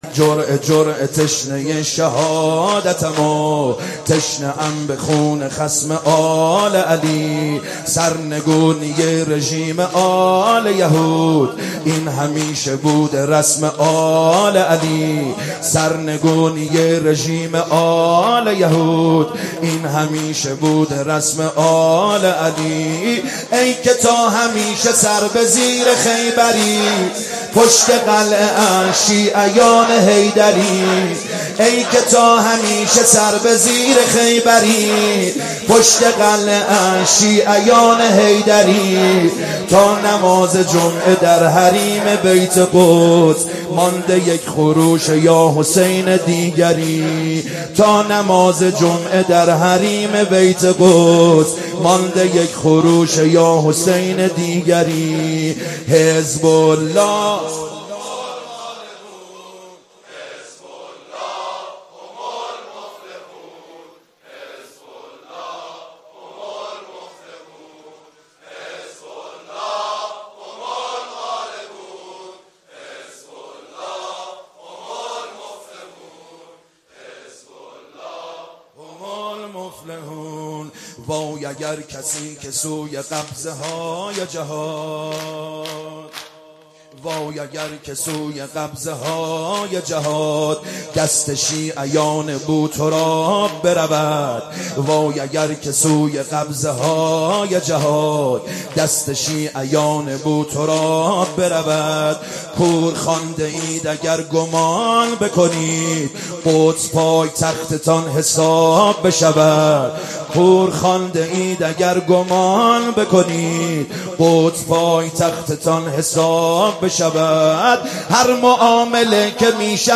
سینه سه ضرب